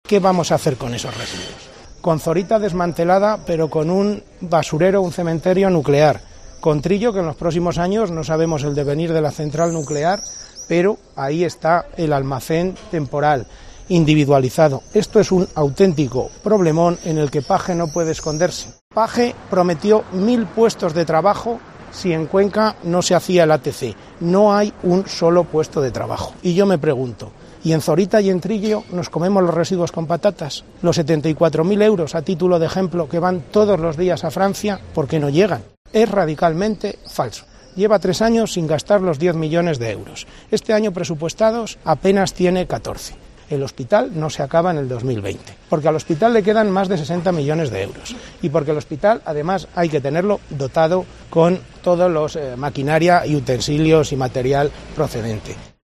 Pues bien, esta mañana el diputado regional del PP, Lorenzo Robisco, ha calificado esta decisión de “imprudente y negligente” y ha planteado una pregunta.(AUDIO DE ROBISCO SOBRE ATC EN TÉRMINOS DE SEGURIDAD)Además de las cuestiones de seguridad, Robisco ha lamentado el perjuicio económico que va a provocar la decisión del Gobierno de paralizar el ATC.(AUDIO DE ROBISCO SOBRE LOS EFECTOS ECONÓMICOS NEGATIVOS DE PARALIZAR EL ATC)Robisco ha hecho estas declaraciones junto a la Delegación de la Junta en Guadalajara, en cuya fachada pegaba un cartel que rezaba “Page, los residuos de Trillo y Zorita llévatelos a tu casa.